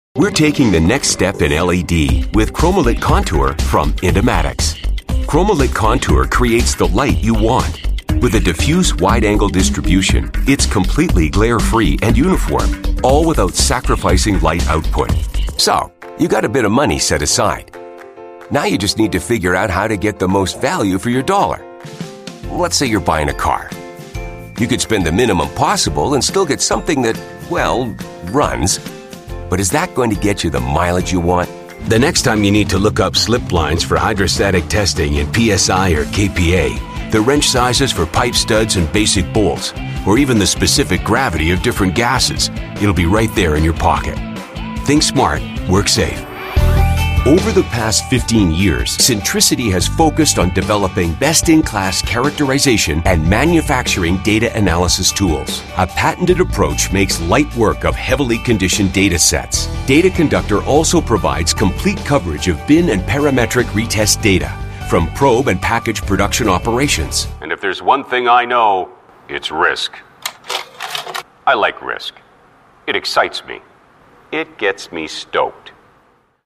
uma voz imponente de barítono canadense — autoritária, confiante e versátil.
Inglês (canadense)
Vídeos Corporativos
Microfone Sennheiser MKH 416
Cabine de voz personalizada
Meia-idade
BarítonoGravesProfundo